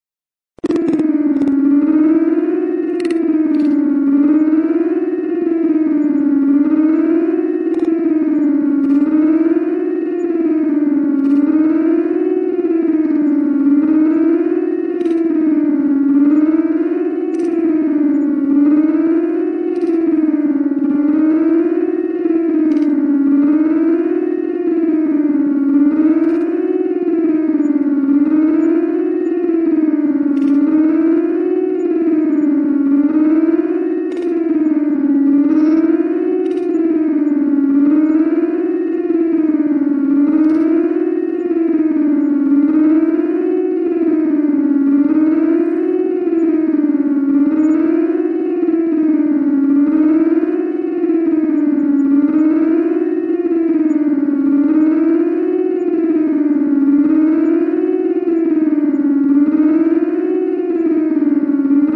Night Siren Sound Button - Free Download & Play